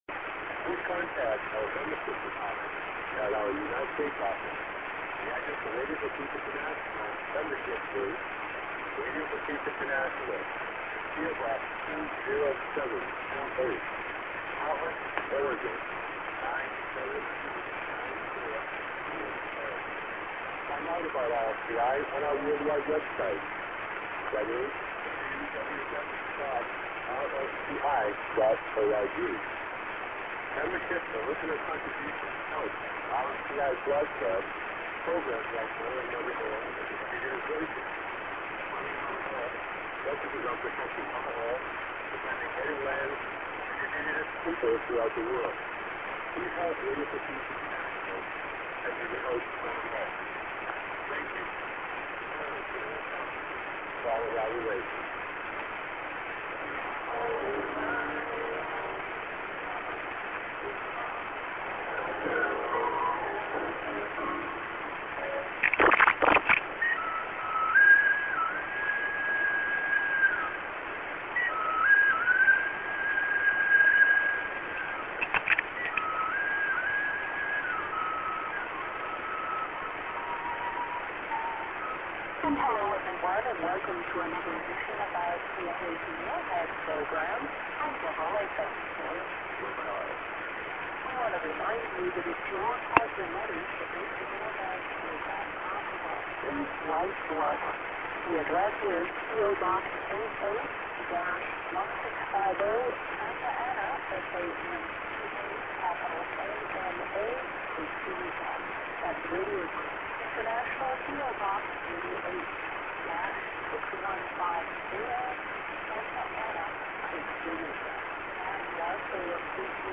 ->ADDR+Web ADDR(man)->ADDR+ID(women)